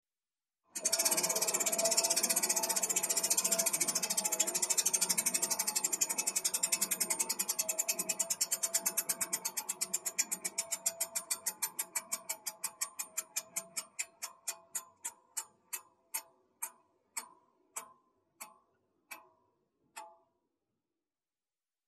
На этой странице представлены звуки колеса фортуны в разных вариациях: от классического вращения до эффектных фанфар при выигрыше.
Шум вращающегося Колеса Фортуны